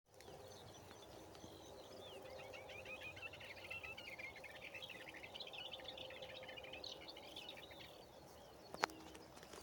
Klusais ķauķis, Iduna caligata
StatussPāris ligzdošanai piemērotā biotopā (P)